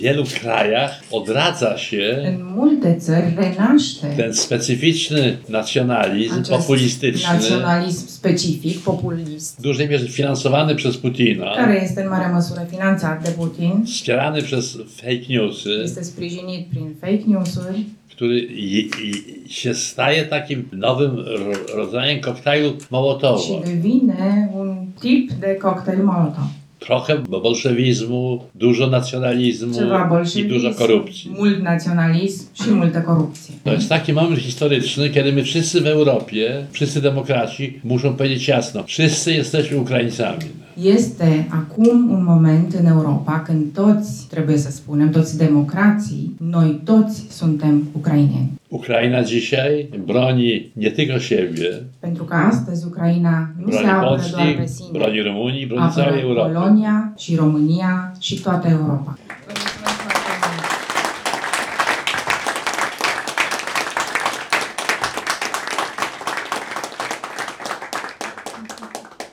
Eseistul polonez a vorbit despre ascensiunea populismului în Europa și a spus că lupta Ucrainei pentru libertate este, de fapt, lupta întregii Europe.